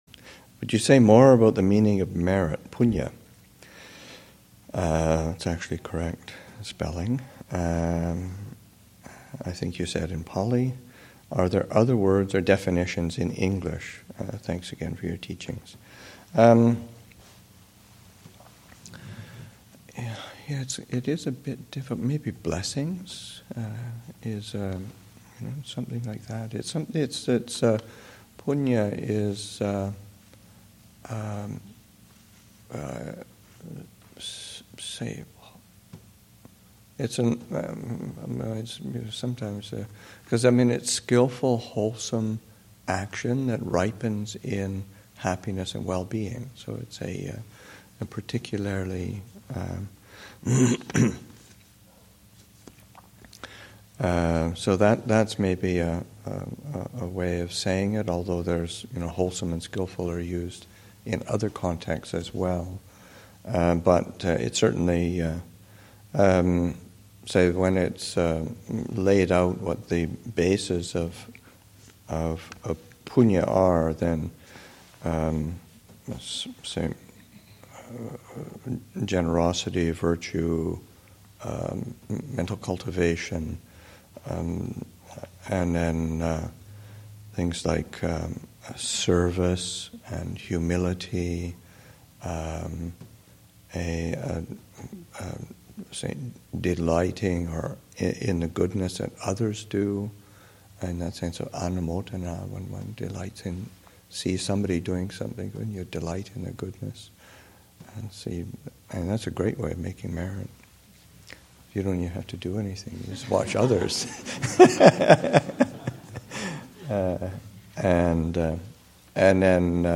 2014 Thanksgiving Monastic Retreat, Session 4 – Nov. 25, 2014